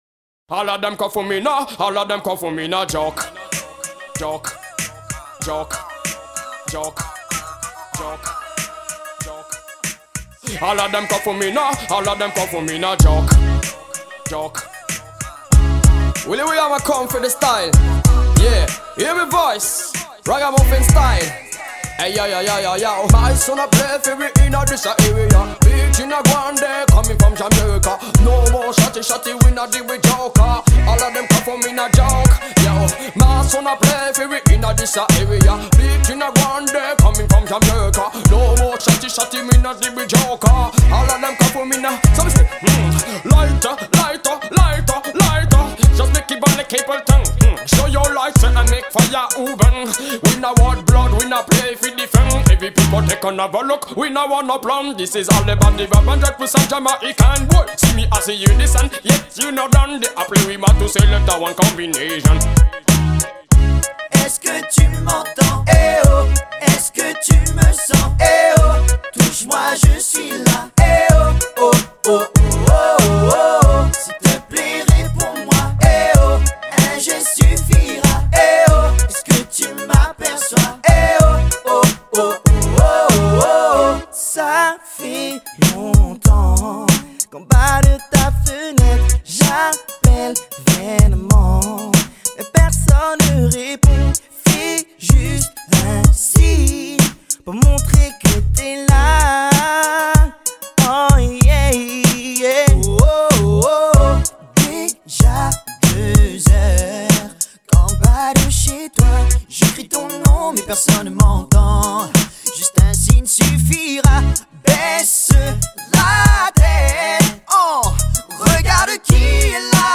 [12/8/2007]一首很好听的黑人说唱的曲子 激动社区，陪你一起慢慢变老！